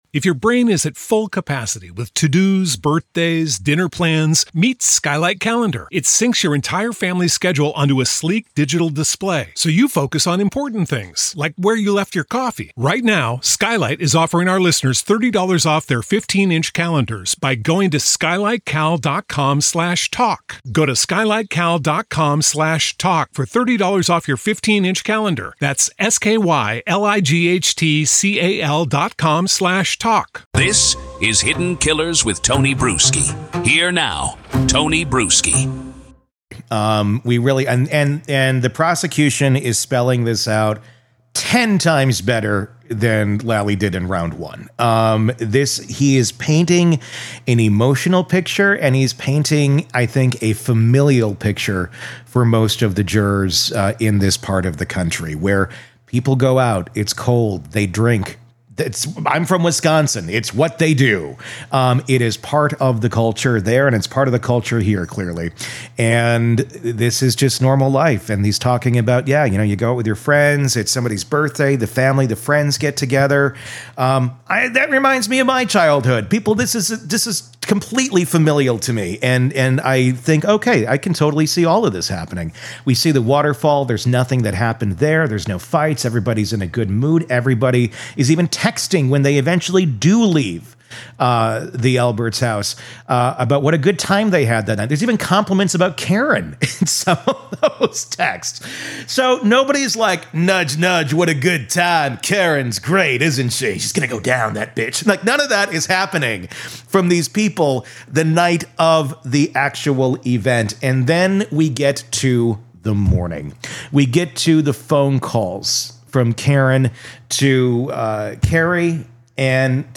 True Crime Today | Daily True Crime News & Interviews / Karen Read’s Defense: Does It Even Make Sense?